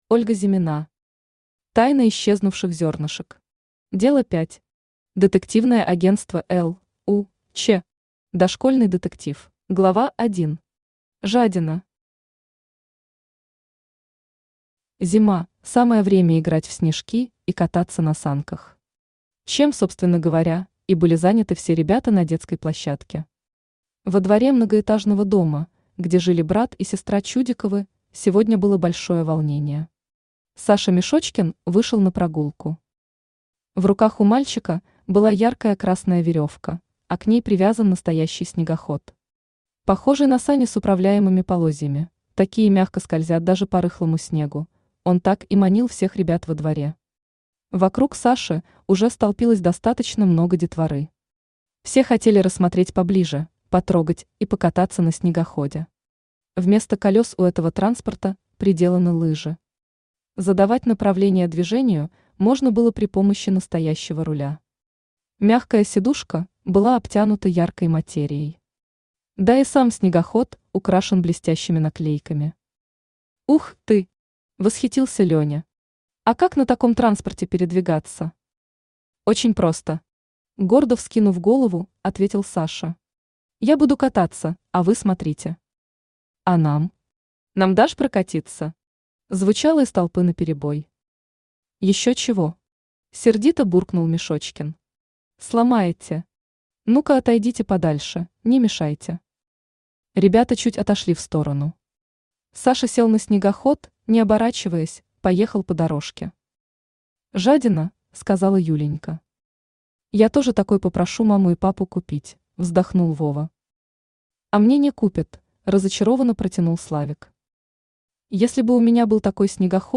Аудиокнига Тайна исчезнувших зернышек. Дело 5. Детективное агентство «Л.У.Ч.». Дошкольный детектив | Библиотека аудиокниг
Дошкольный детектив Автор Ольга Игоревна Зимина Читает аудиокнигу Авточтец ЛитРес.